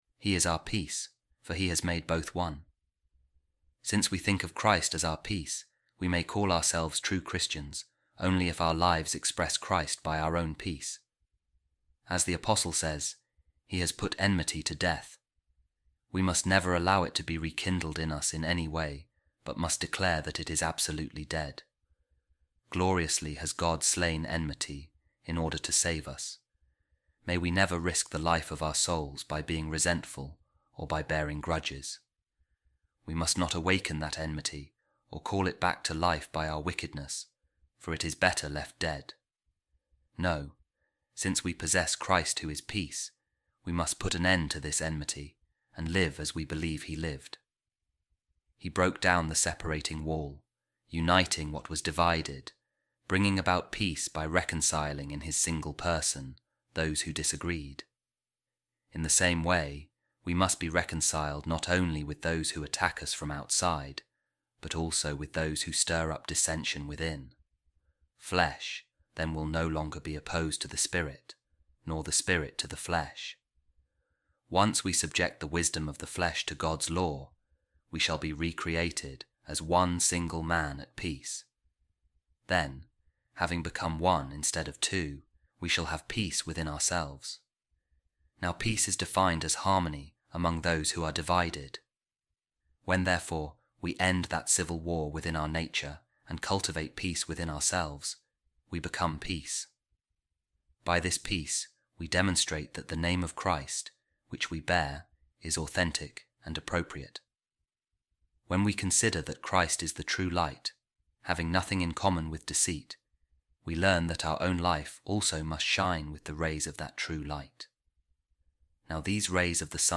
A Reading From The Treatise Of Saint Gregory Of Nyssa On Christian Perfection | We Have Jesus Christ Who Is Our Peace And Our Light